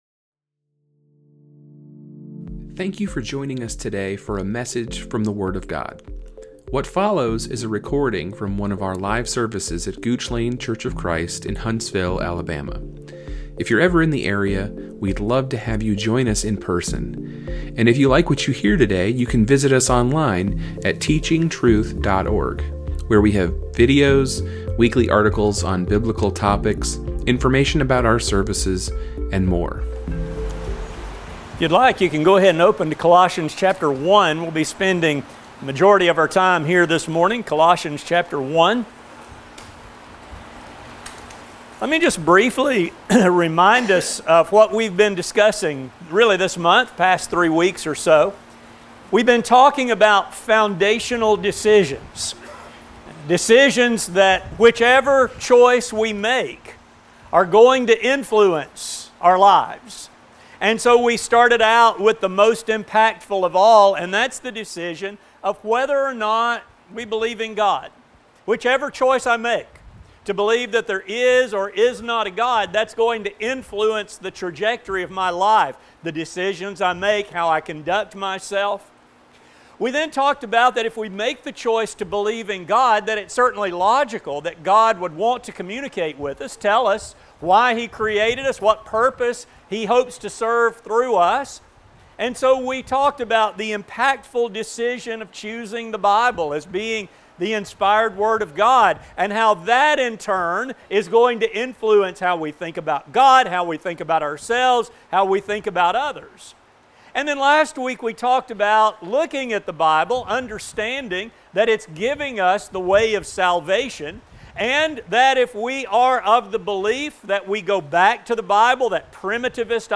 This sermon will explore Paul’s words to these ancient Christians and show how what was relevant then remains relevant today. It will examine the foundations on which a church must be built and the means by which its health can be evaluated.